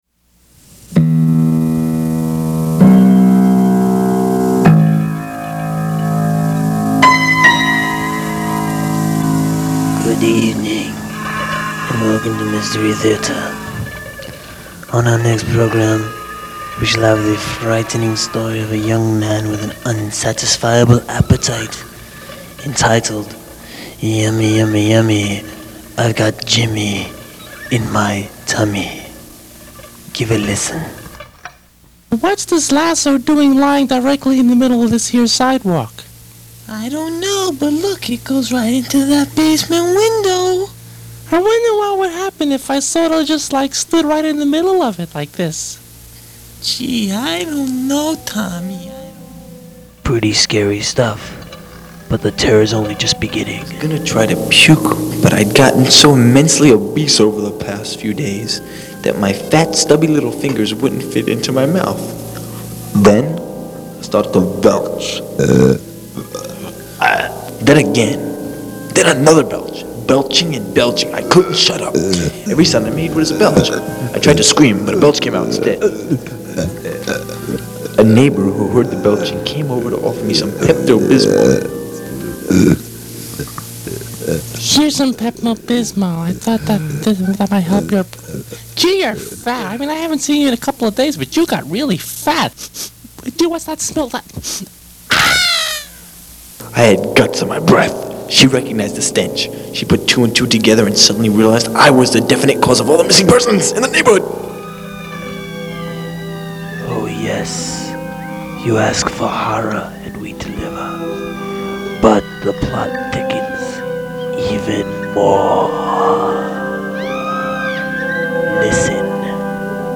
Mystery Theater Commercial.mp3